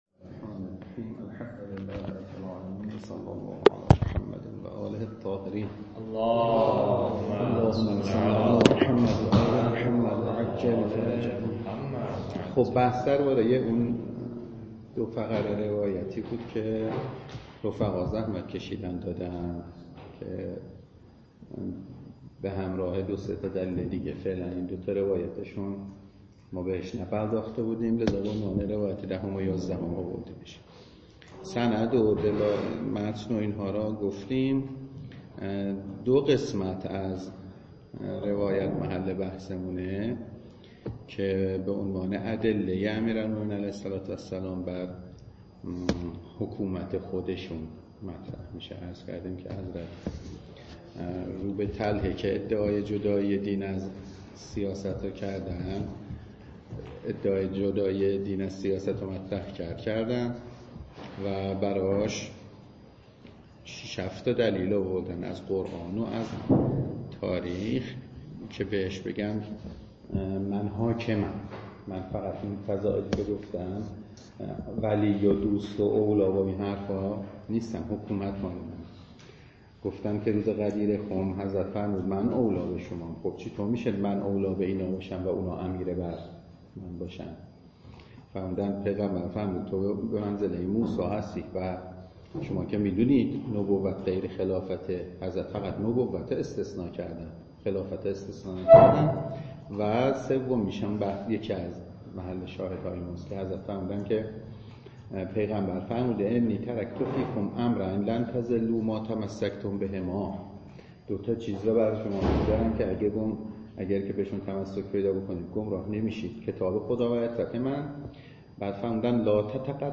درس خارج فقه ولایت فقیه